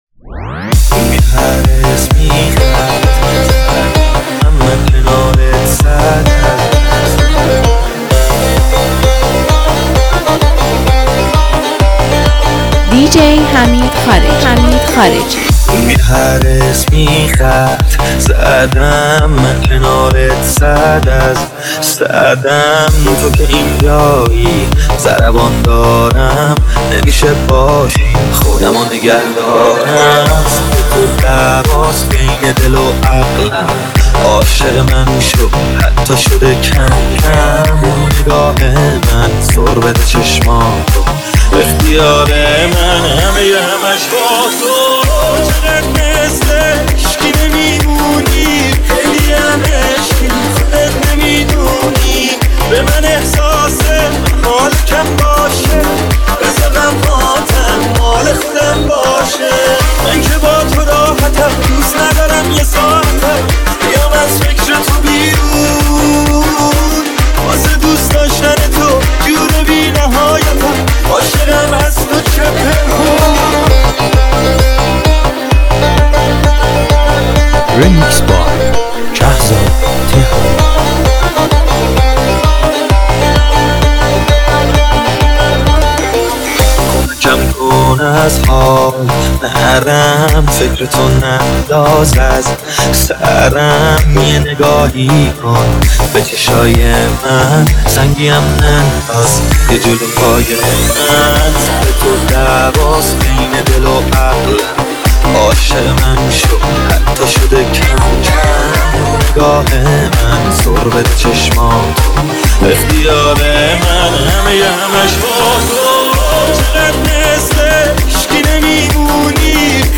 ریمیکس آهنگ